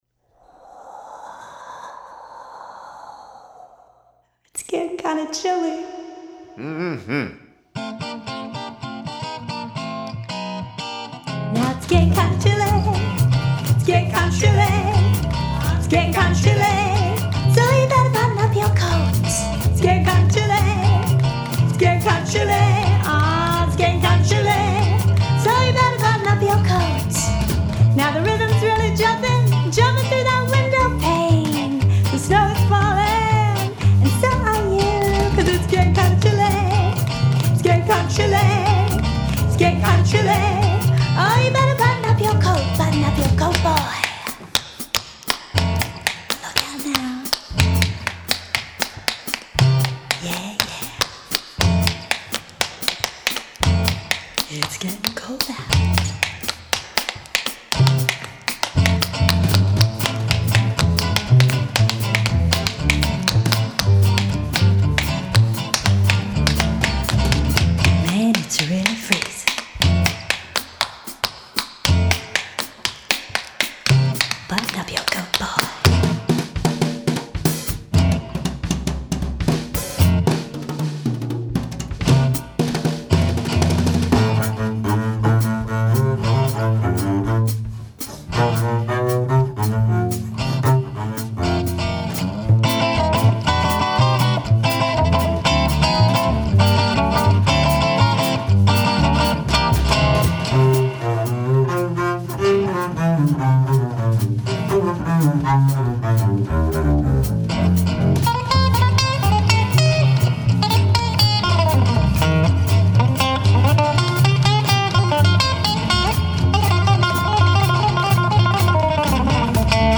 Jivin’ n’ Swinging – Sparkling with Tap-Dance Accents